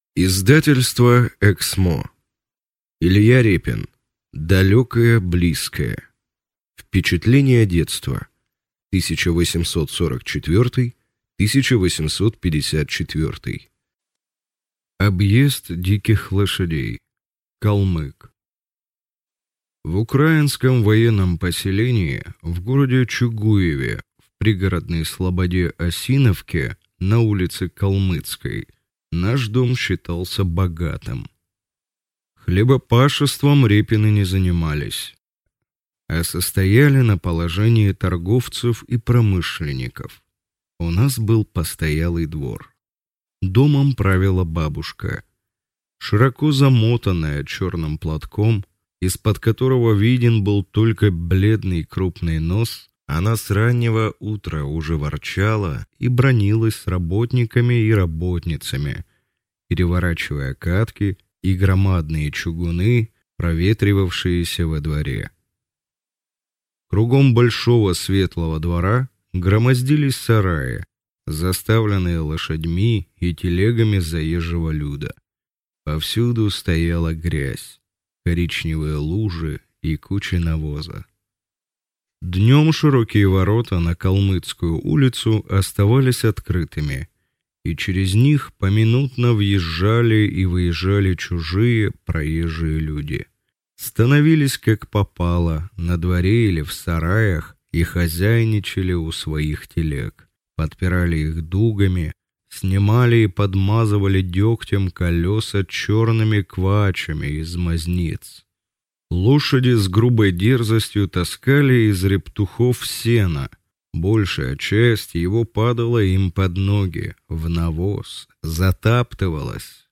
Аудиокнига Далекое близкое. Том 1 | Библиотека аудиокниг